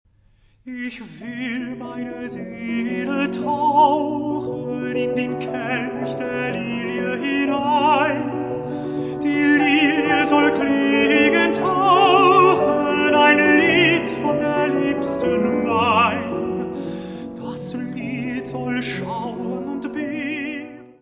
: stereo; 12 cm + folheto
tenor
piano
Music Category/Genre:  Classical Music